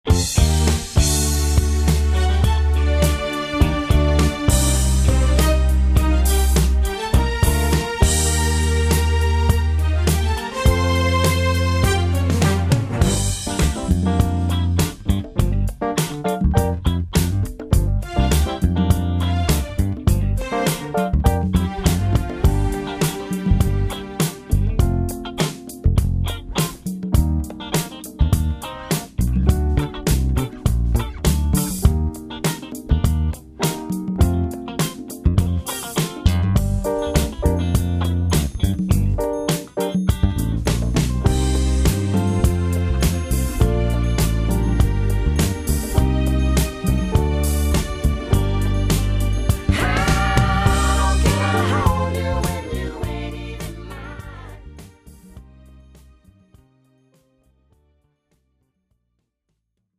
축가 및 결혼식에 최적화된 고품질 MR을 제공합니다!